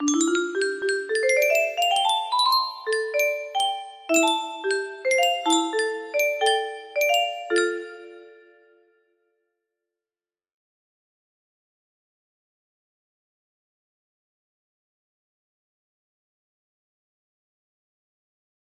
test1 untitled music box melody